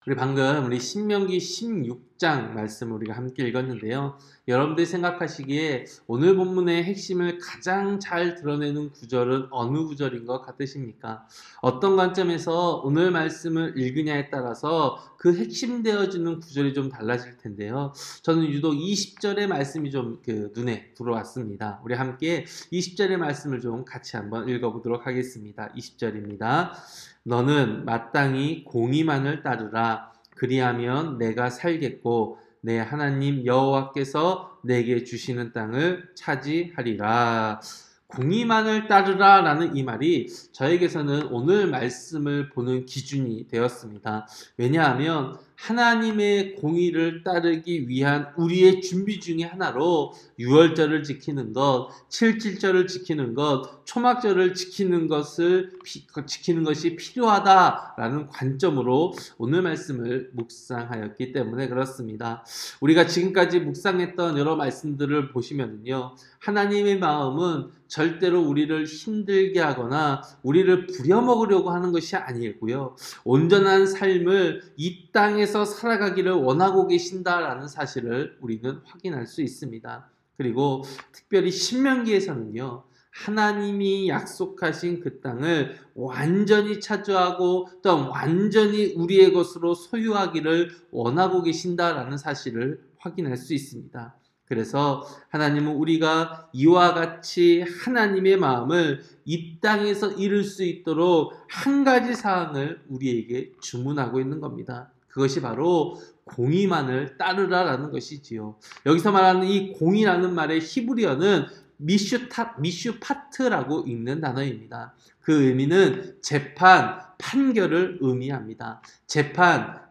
새벽설교-신명기 16장